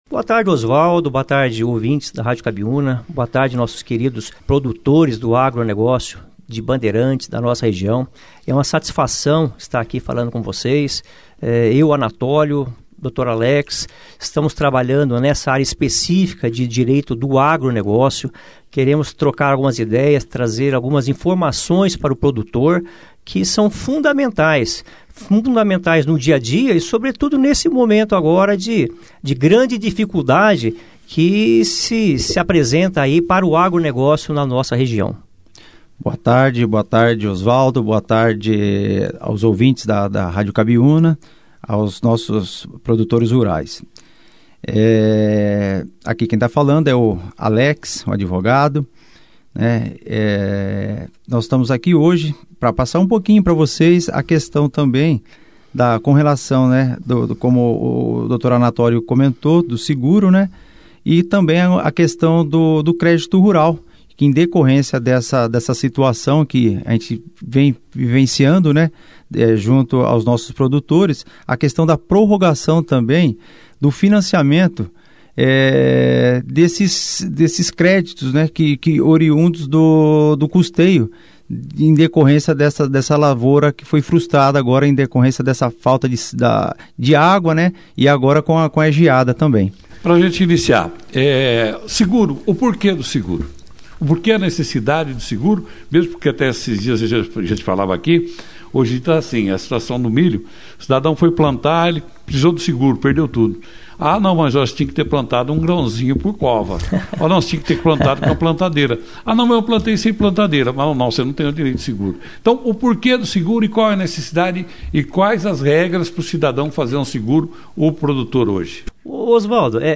Consultores de agronegócio falam sobre a importância do agricultor buscar seus direitos em seguros e financiamentos